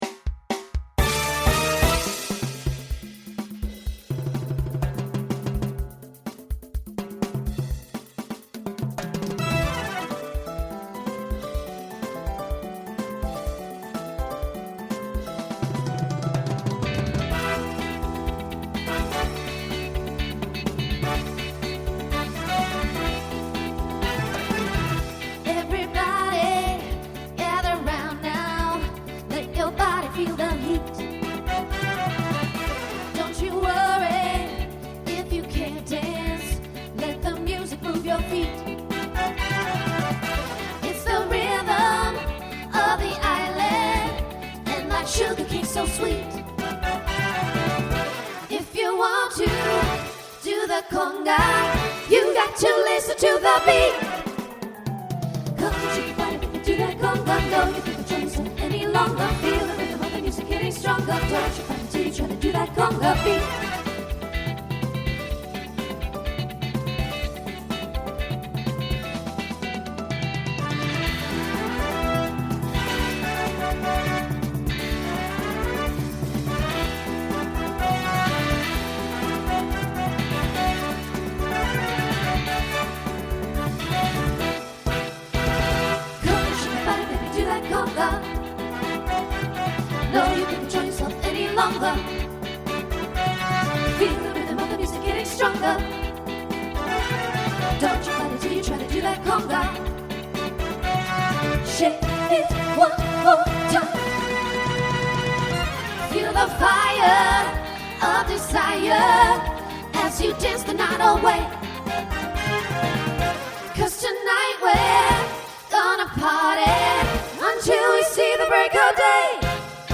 Voicing SSA Instrumental combo Genre Latin , Pop/Dance